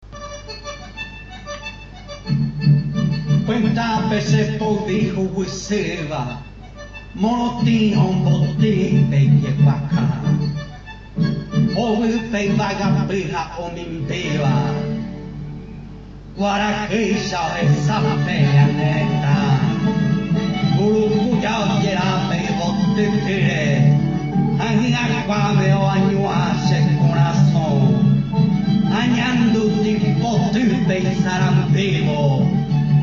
Here’s a recording of part of a poem in a mystery language.
Unfortunately the musical accompaniment makes it a bit tricky to hear the words clearly.